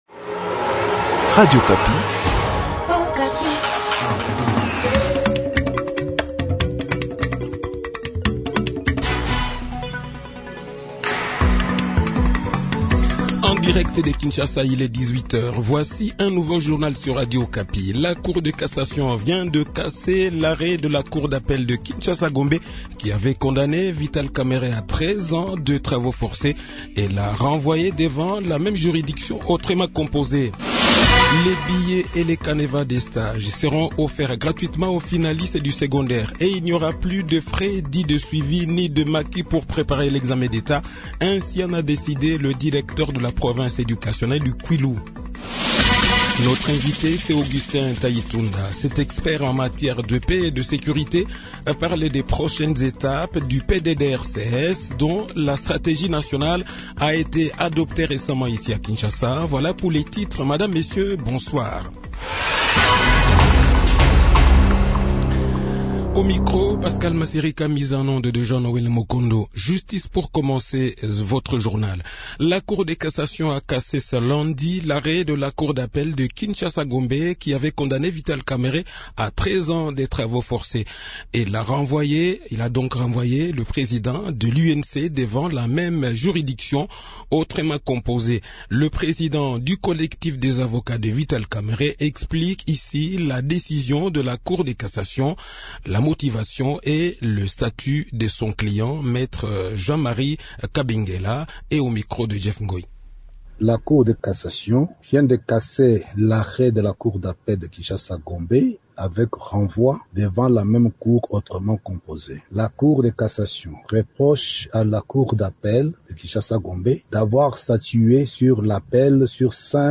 Le journal de 18 h, 11 Avril 2022